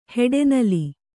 ♪ heḍe nali